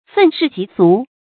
注音：ㄈㄣˋ ㄕㄧˋ ㄐㄧˊ ㄙㄨˊ